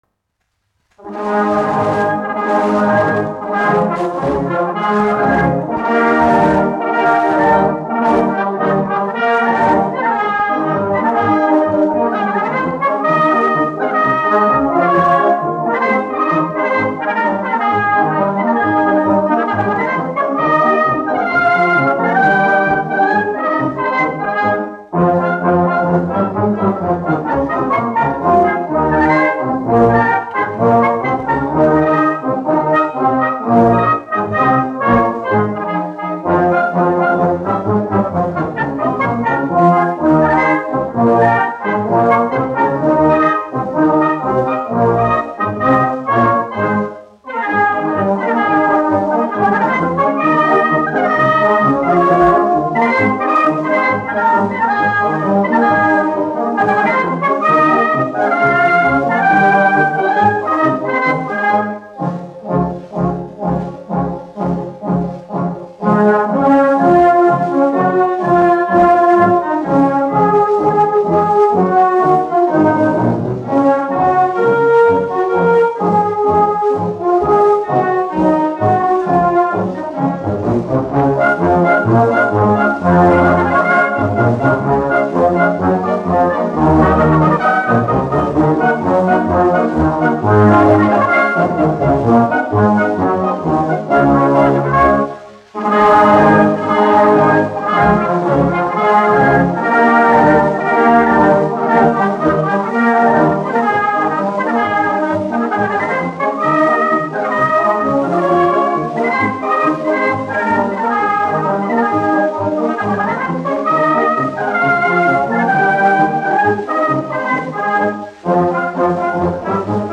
1 skpl. : analogs, 78 apgr/min, mono ; 25 cm
Marši
Pūtēju orķestra mūzika